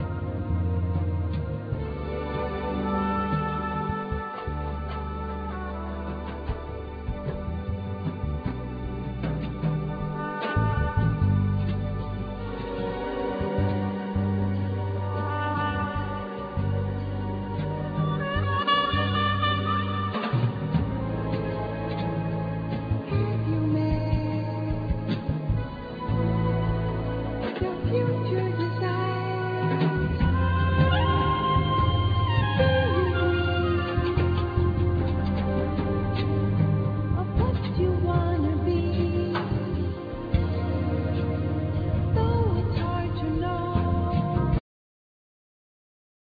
Trumpet
Piano & Keyboard
Basses
Percussion,Drums,Voice
Drums